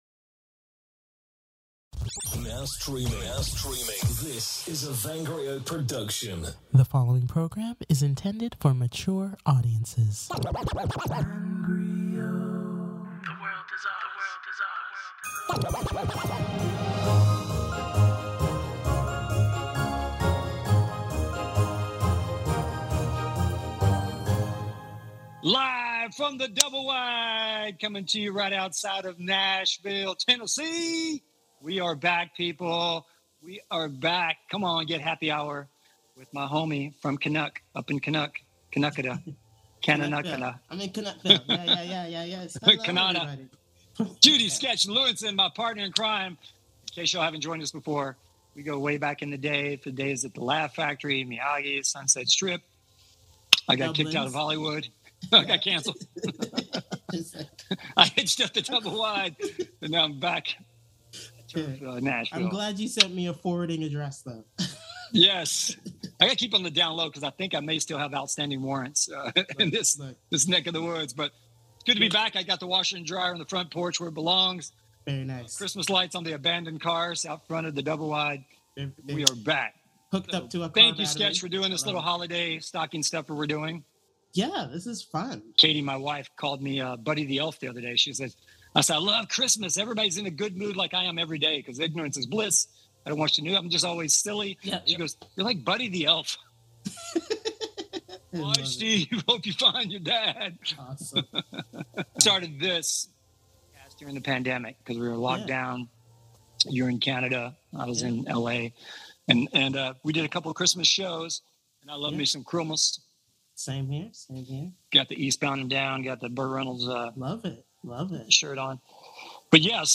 interviews guests